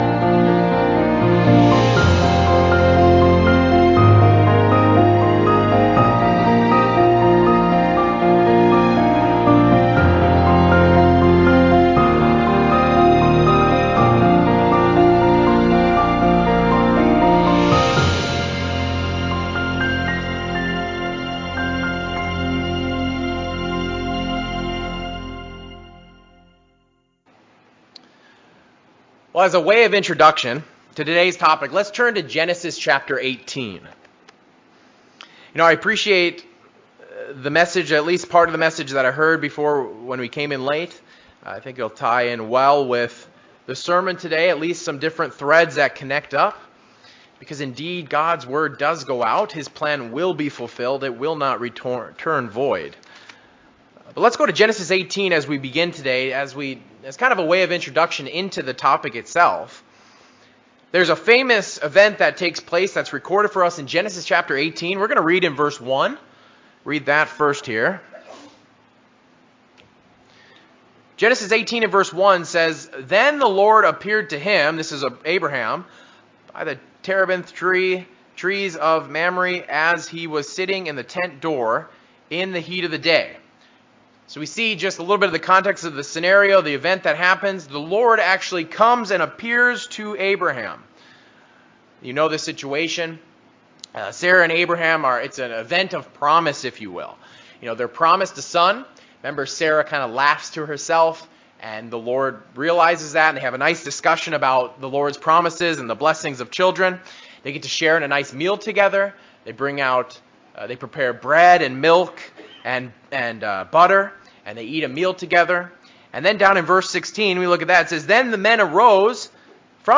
In this sermon, we will review the doctrinal understanding of how God interacted with humans in the Old Testament through the lens of the Angel of God's presence.